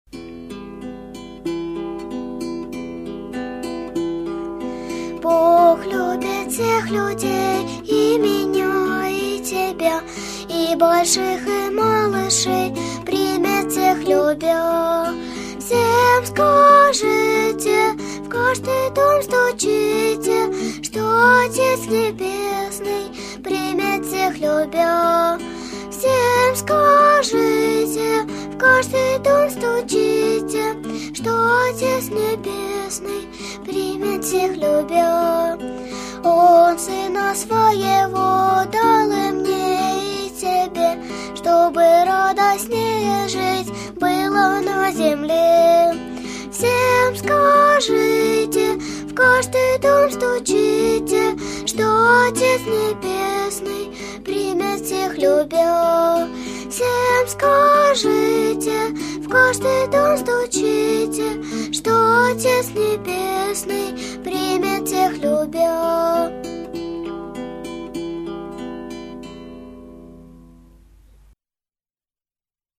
Гитара
Вокал